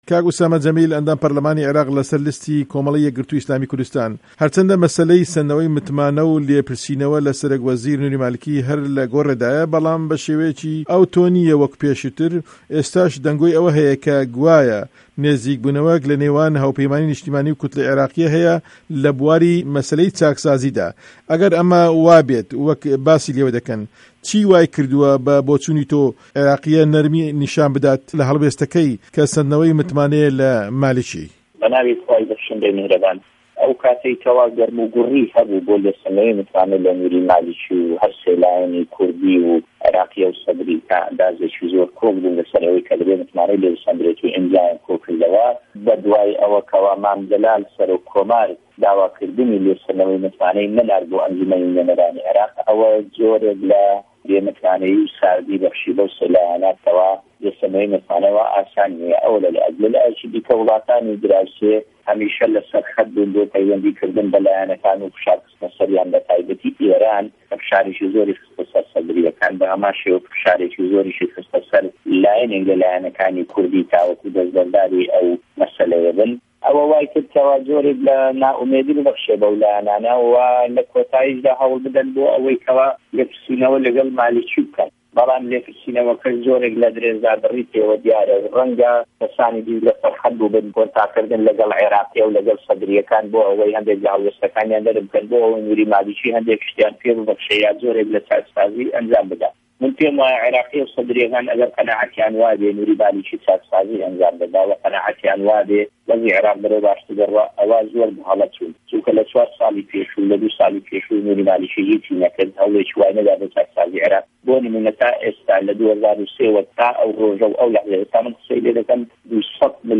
گفتوگۆ له‌گه‌ڵ ئوسامه‌ جه‌میل 24 ی حه‌وتی 2012